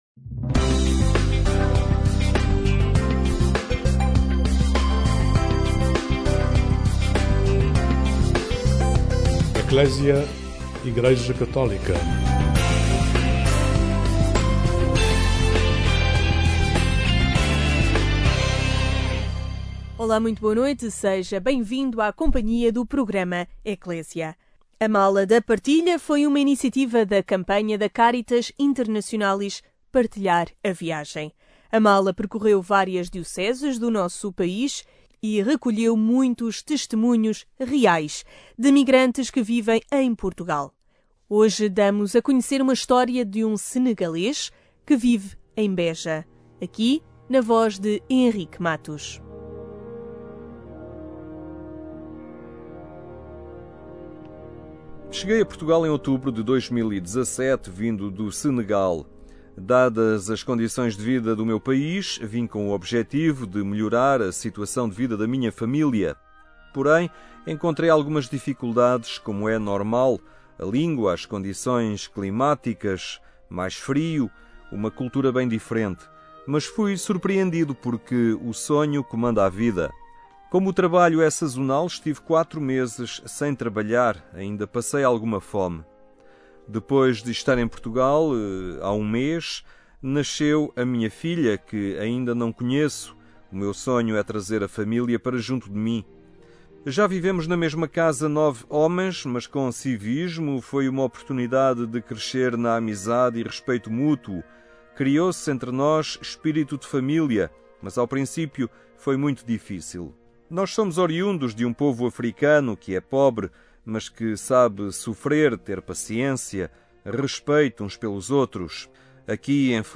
Iniciamos este programa Ecclesia com um testemunho real de um imigrante senegalês que vive em Portugal e que partilhou a sua história na Mala da partilha, iniciativa integrada na campanha da Caritas Internationalis “Partilhar a Viagem”.